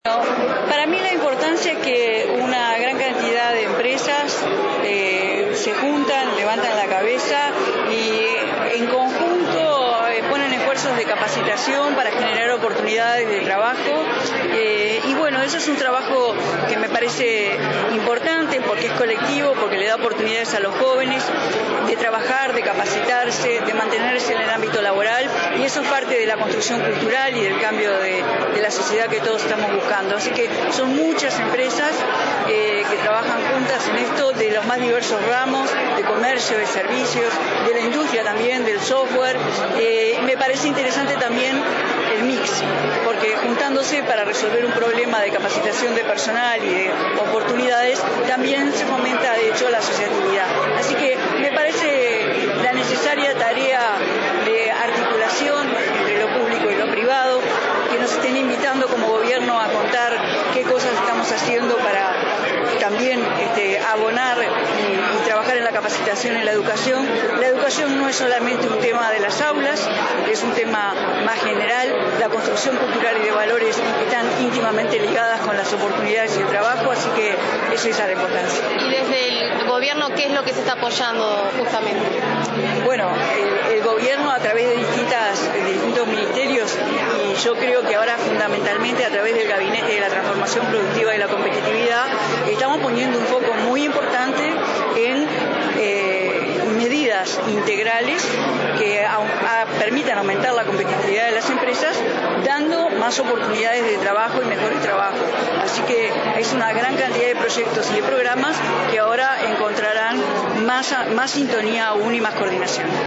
La ministra de Industria, Carolina Cosse, subrayó que en un mundo cada vez más complejo hay que construir oportunidades basadas en el conocimiento, el cimiento para generar infraestructuras que nadie nos podrá quitar. Durante una cena de la fundación Forge, destacó las oportunidades de trabajo que 155 empresas ofrecen a jóvenes a través de dicha fundación y las medidas integrales del Gobierno para mejorar la competitividad.